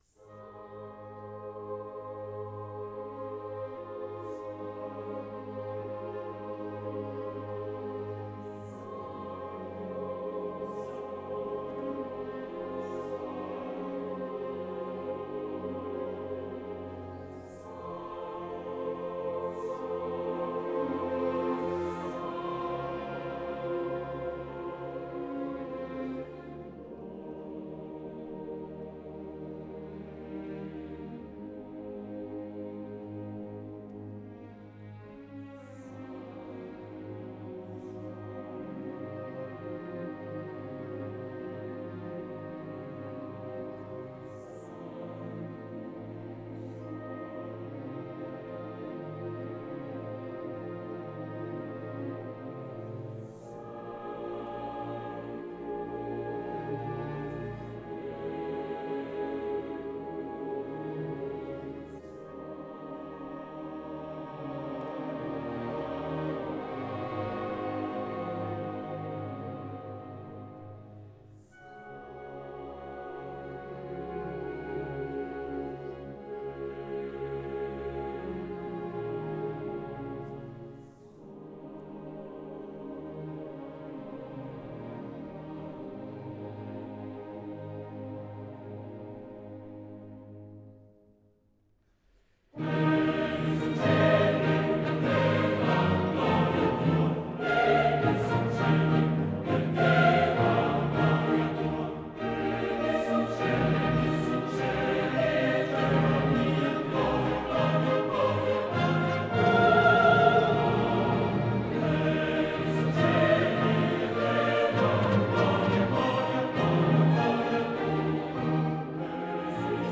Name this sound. Genere: Classical.